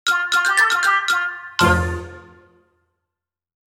game over.mp3